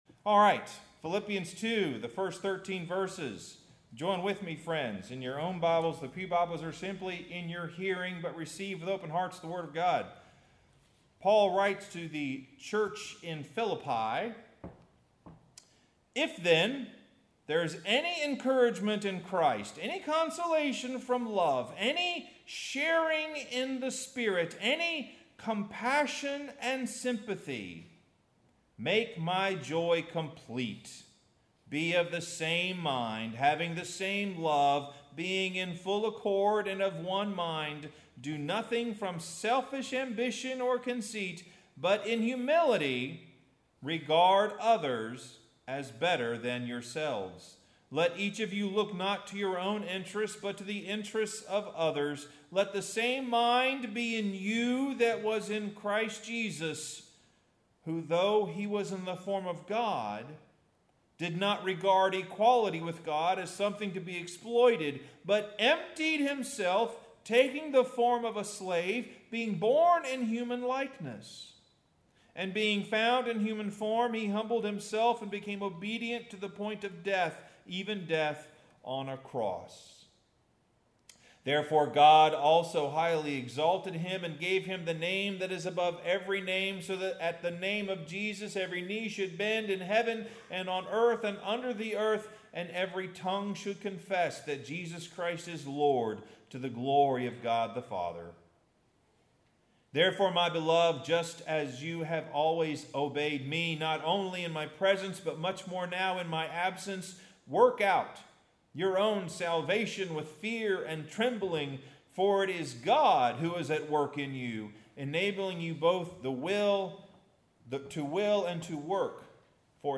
Sermon – Where is God?